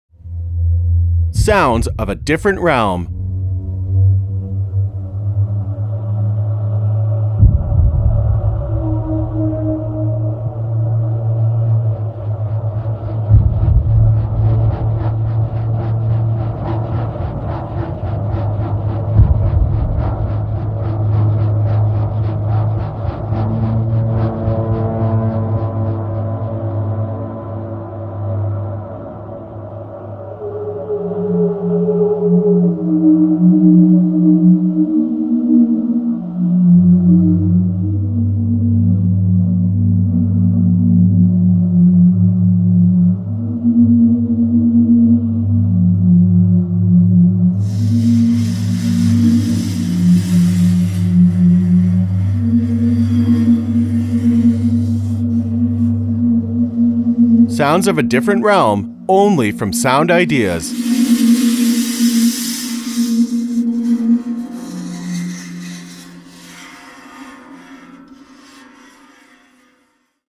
Раздаваемый материал: Звуковые библиотеки
• WAV 16 bit | 44.1 kHz | 1.41 mbps | stereo
sounds of a different realm with vo.mp3